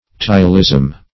Ptyalism \Pty"a*lism\ (t[imac]"[.a]*l[i^]z'm), n.
ptyalism.mp3